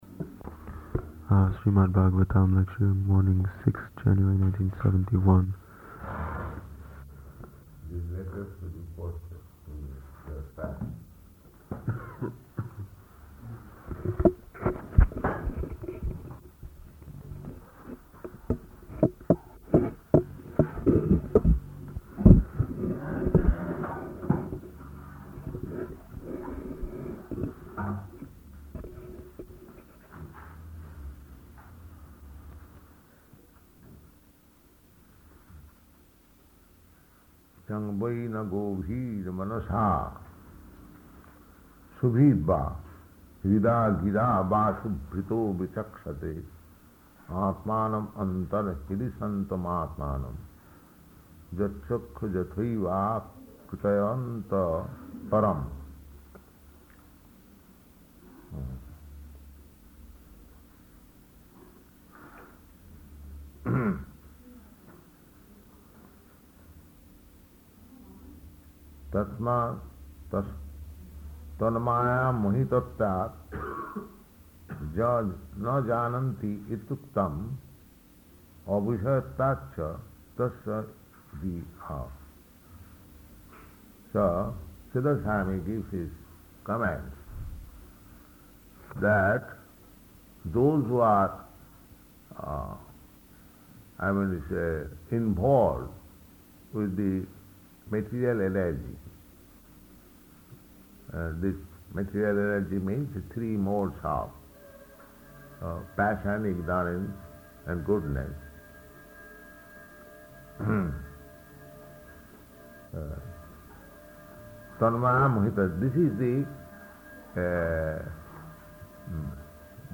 Śrīmad-Bhāgavatam 6.3.16–17 --:-- --:-- Type: Srimad-Bhagavatam Dated: February 6th 1971 Location: Gorakphur Audio file: 710206SB-GORAKPHUR.mp3 Devotee: [introducing recording] Śrīmad-Bhāgavatam lecture, morning, 6th February, 1971.